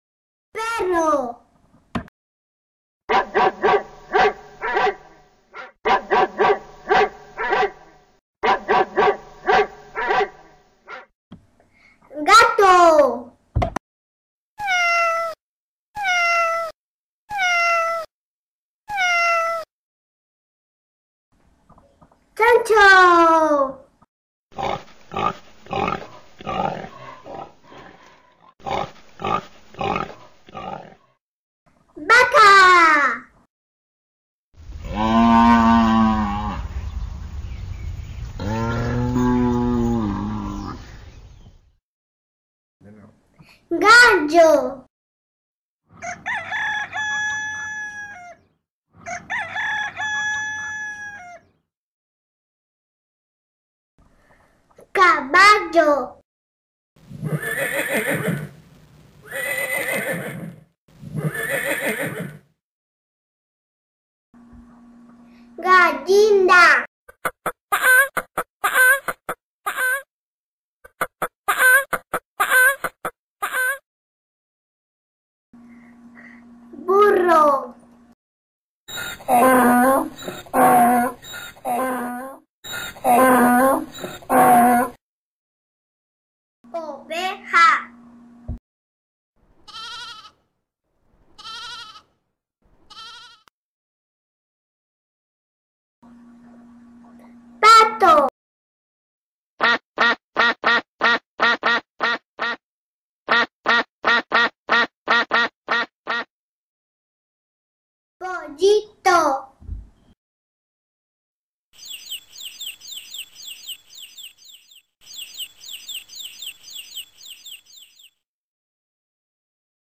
Sonidos de Animales para bebes   Aprende los Animales.mp3